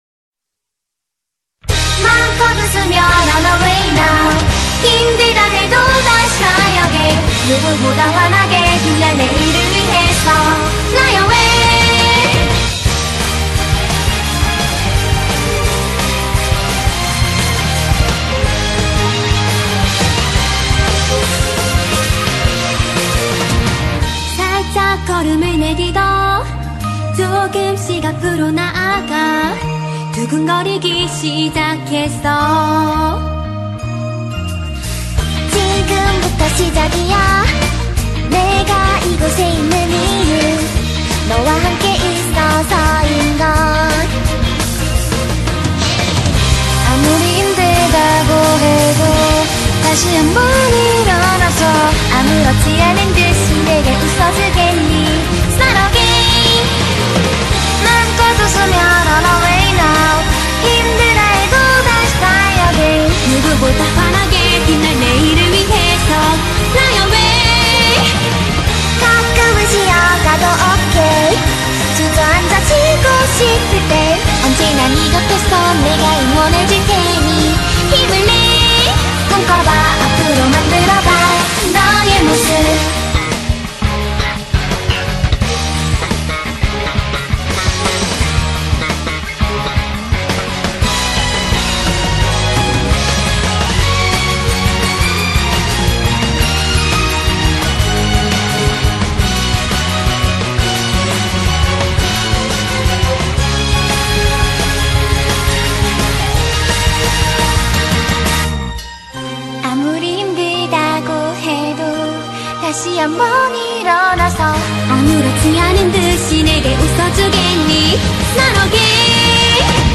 BPM86-172
Audio QualityCut From Video